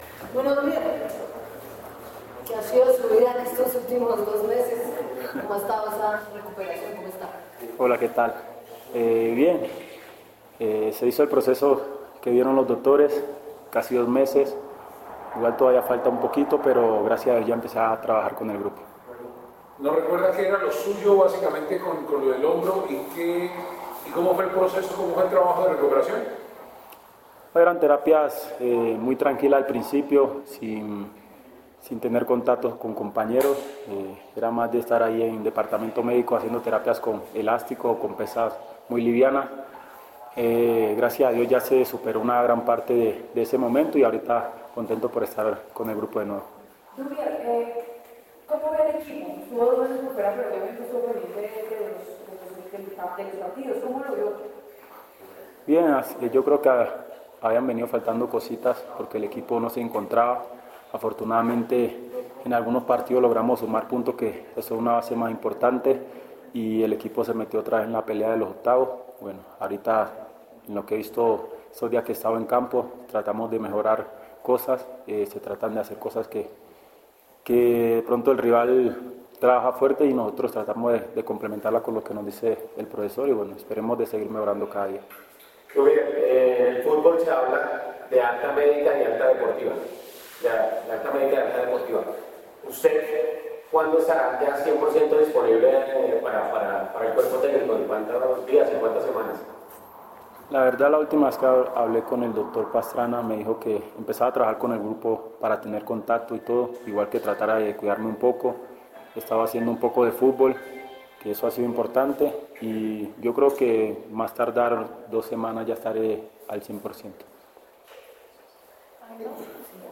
El delantero, goleador de Millonarios en el primer semestre del año, atendió a los medios de comunicación este miércoles en la sede deportiva del club. Riascos habló de su lesión, de la recuperación, de lo que ha venido trabajando para estar a punto, de su regreso a trabajos con pelota.
duvier-riascos-previo-huila.mp3